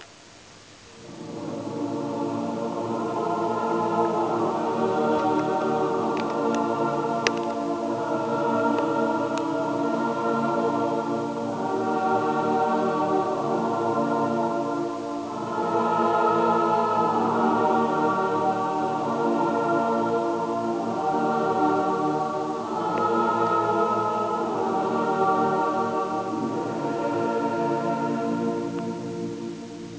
Слике, аудио и видео записи са концерта 5 јуна, 2006 г.